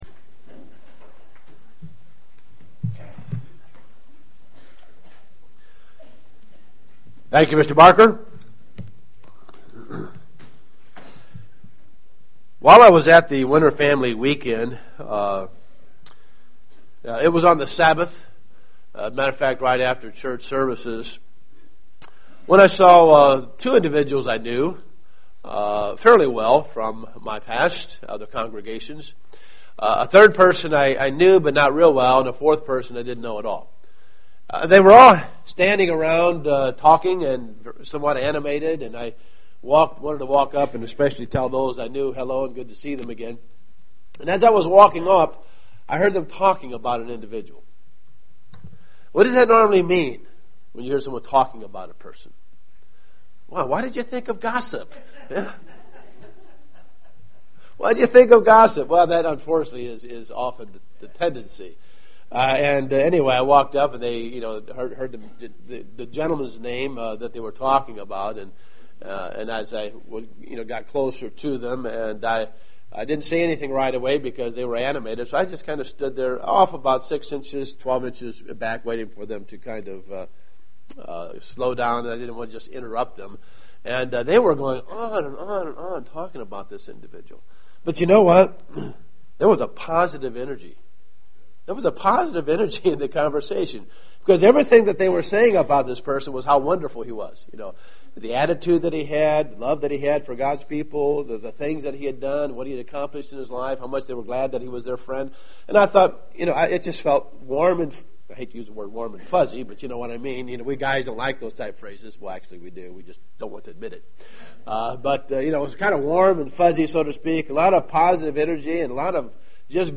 Given in Dayton, OH
Print A look back into the subject of Gossip UCG Sermon Studying the bible?